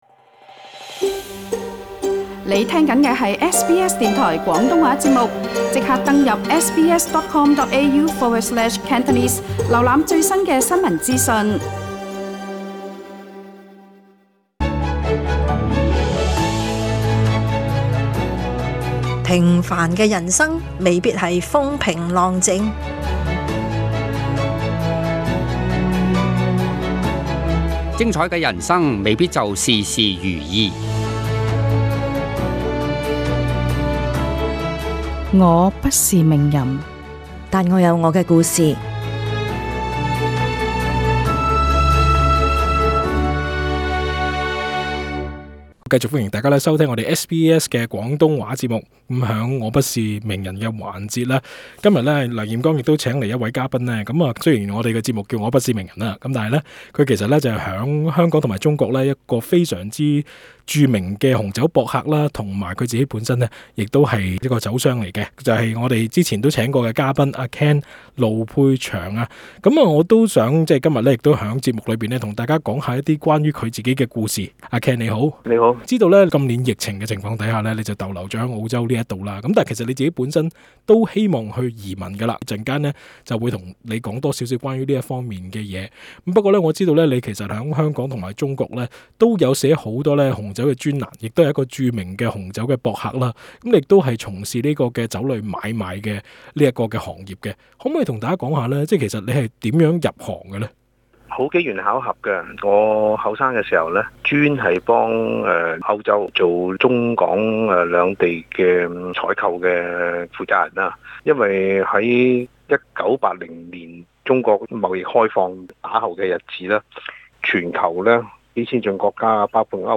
更多內容請收聽足本錄音訪問。